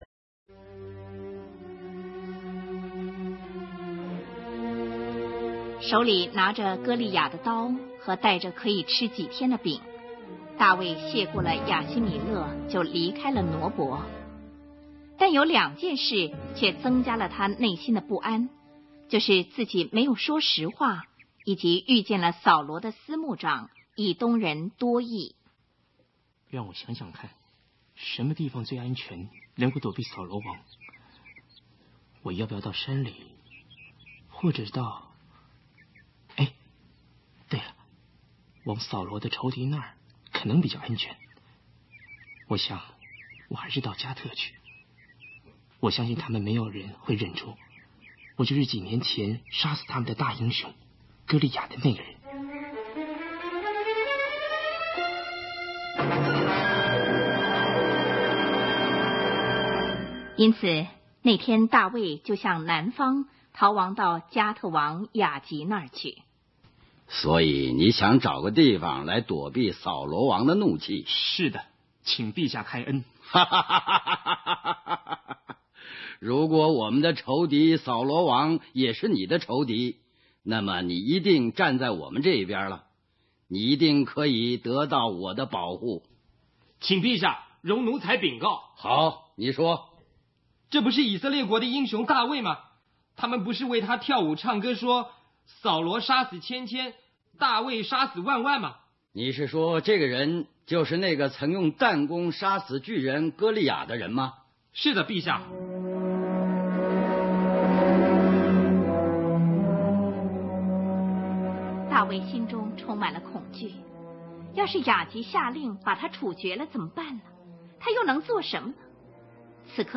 圣经故事广播剧
共270集，其中《旧约》故事 150集，《新约》故事 120集；全部为标准国语的MP3。